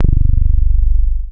02 Synther 2 G.wav